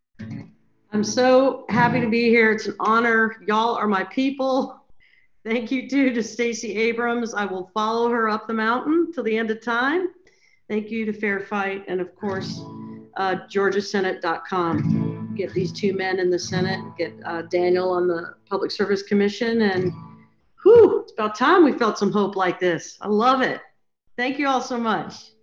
(audio captured from zoon meeting)
08. talking with the crowd (emily saliers) (0:25)